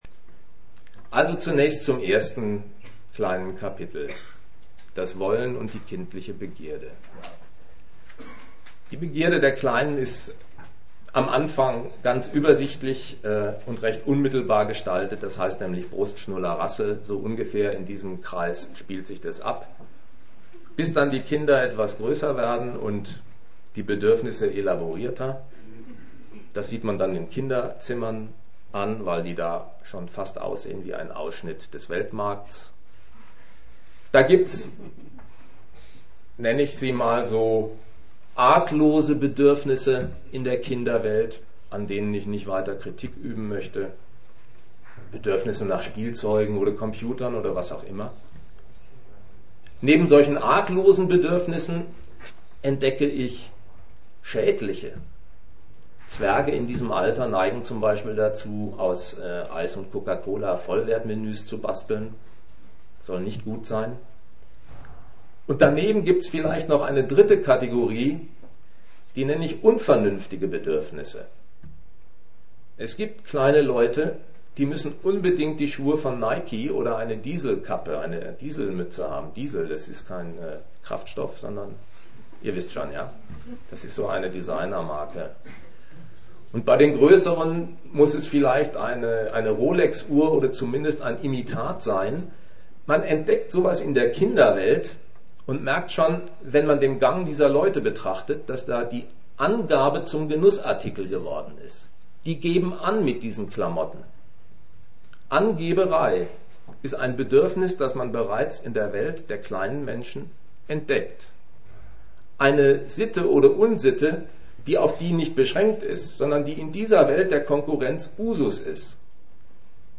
Die Schule: erste Erfahrungen mit der realen Konkurrenz - Leistungen der Erziehung, wirkliche wie erhoffte - Kann man mit Erziehung die Gesellschaft verändern?Teil 6. Diskussion